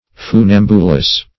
Funambulus \Fu*nam"bu*lus\n.